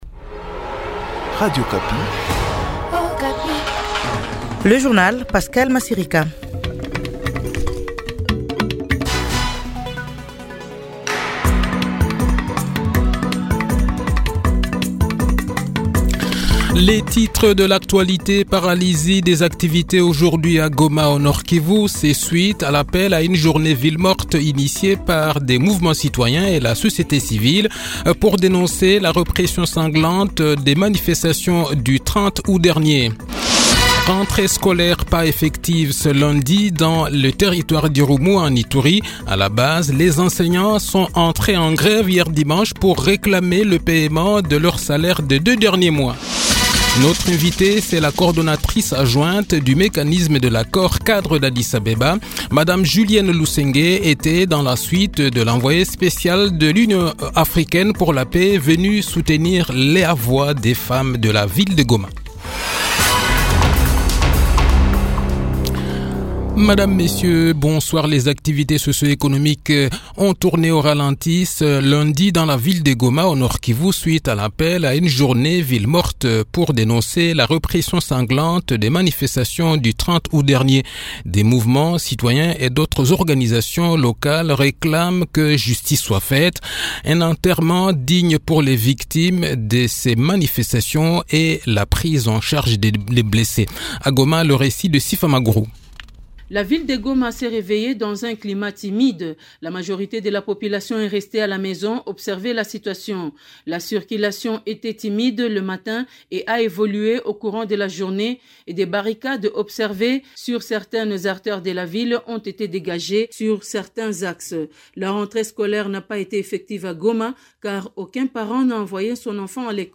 Journal Soir
Le journal de 18 h, 4 Septembre 2023